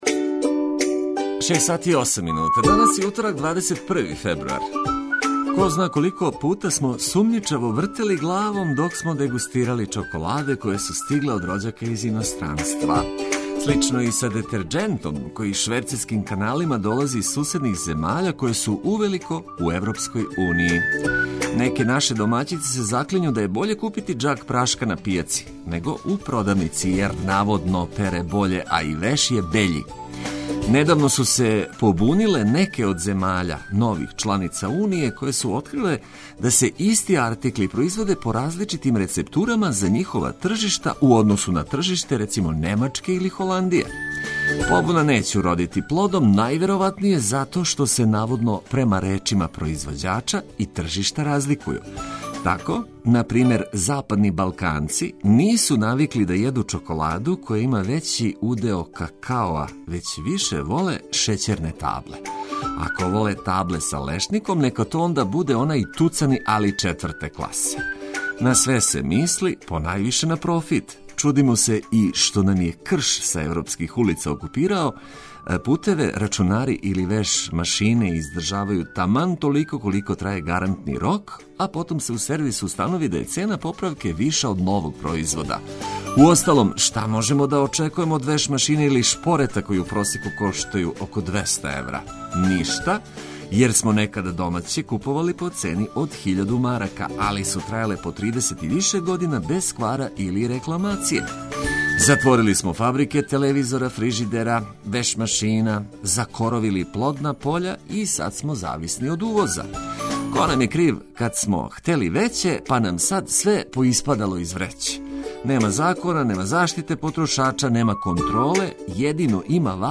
Током јутра важне информације уз музику која ће разведрити облачно јутро.